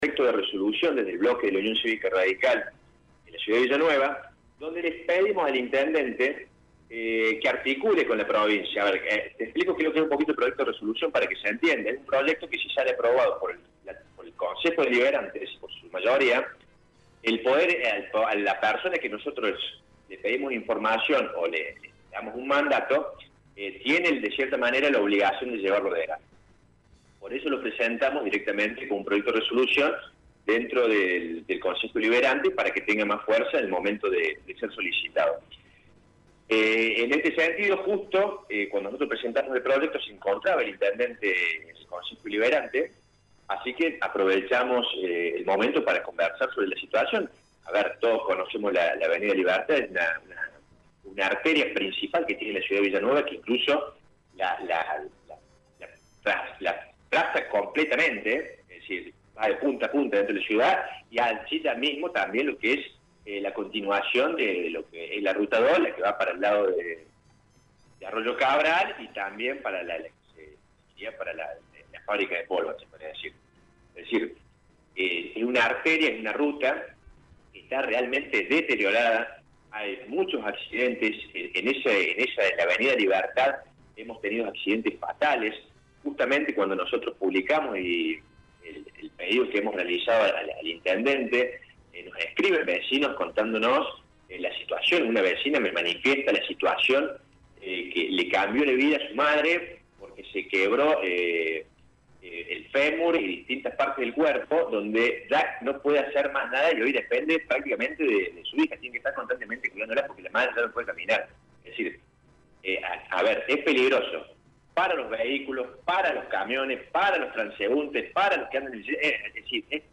Radio Show dialogó con el edil del partido, Ignacio Tagni, quien explicó que las huellas que se formaron por el paso de camiones, son un peligro para quienes transitan por el sector.